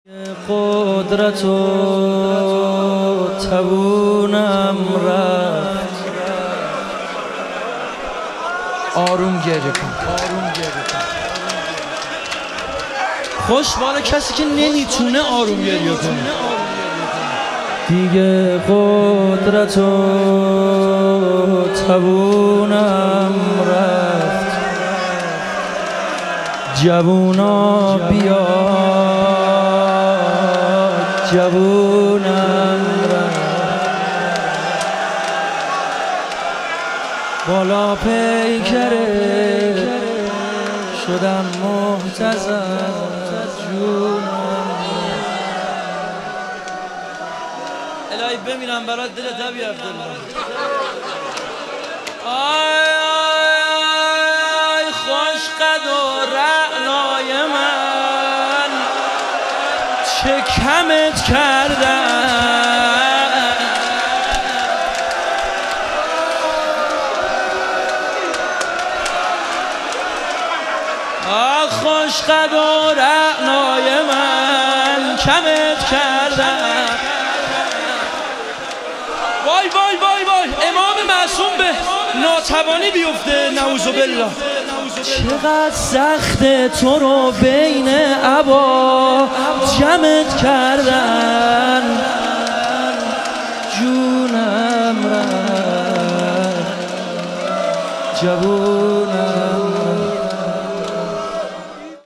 شهادت امام کاظم علیه السلام - روضه